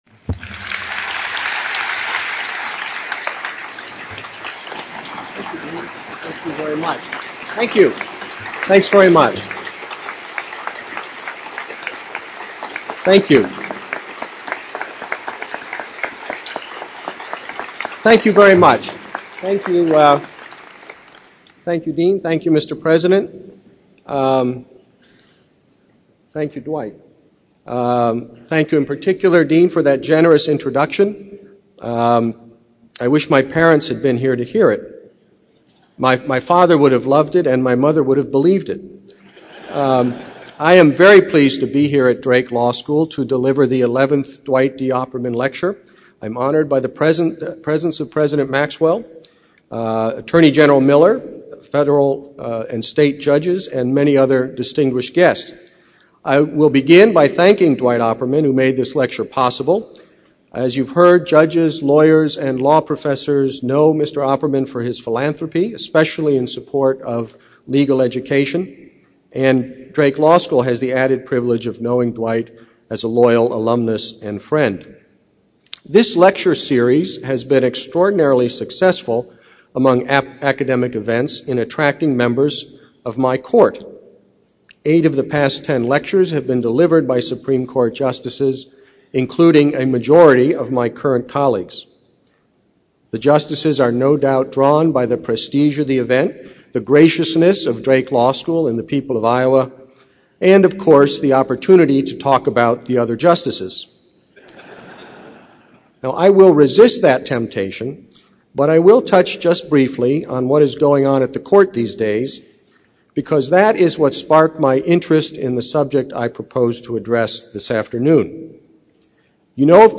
The chief justice of the U.S. Supreme Court suggests the advent of modern technology is “powerful,” but is no substitute for independent thought. Chief Justice John Roberts delivered a half-hour-long public lecture at Drake University Thursday afternoon.